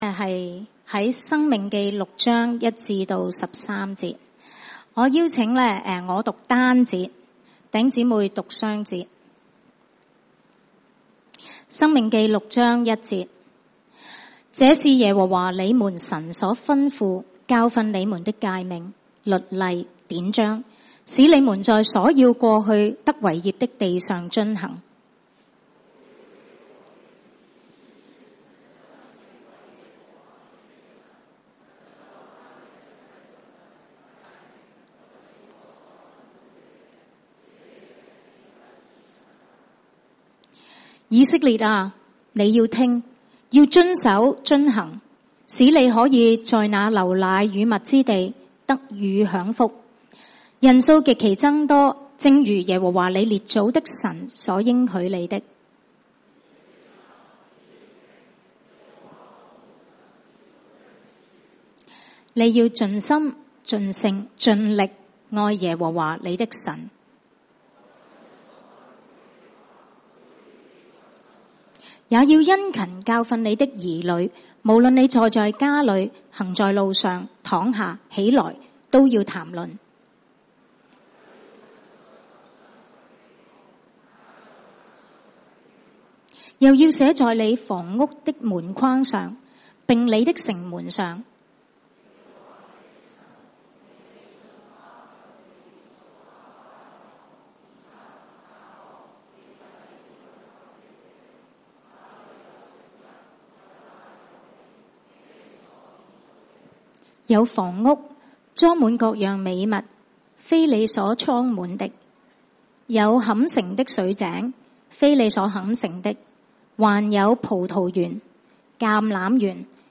華埠粵語三堂 標籤